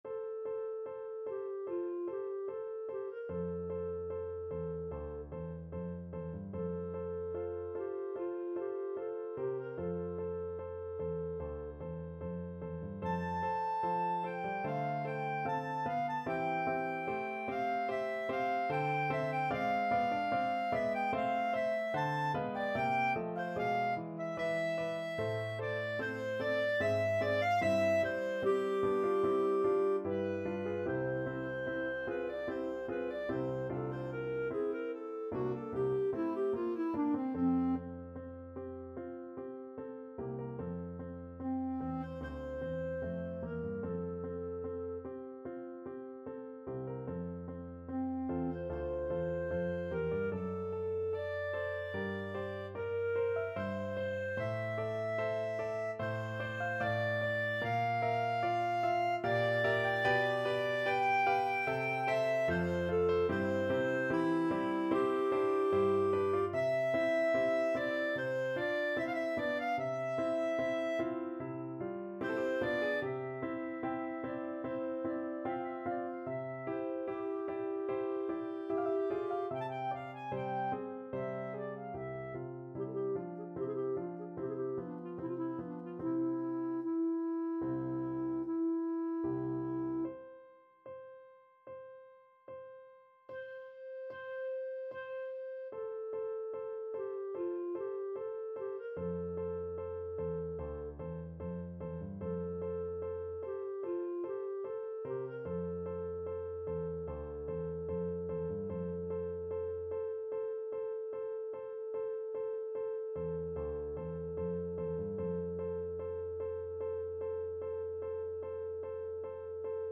Clarinet
G major (Sounding Pitch) A major (Clarinet in Bb) (View more G major Music for Clarinet )
~ = 74 Moderato
4/4 (View more 4/4 Music)
Classical (View more Classical Clarinet Music)